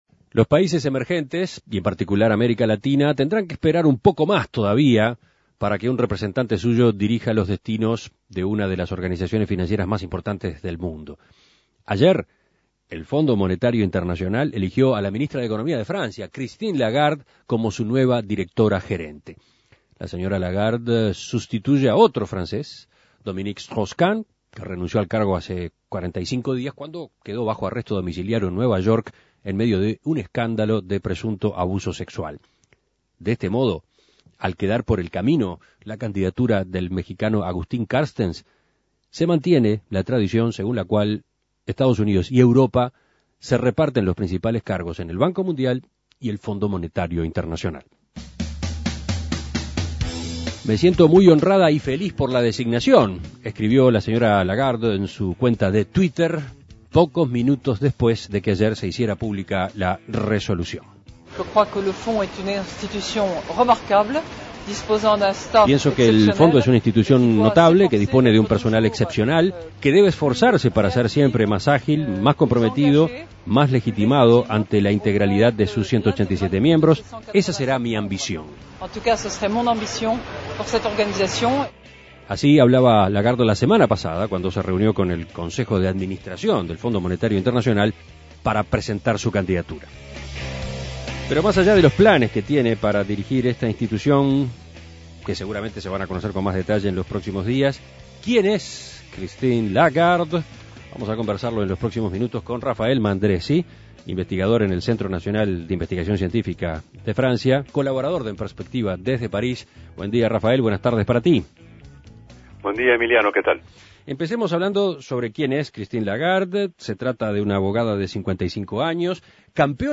colaborador de En Perspectiva desde Paris.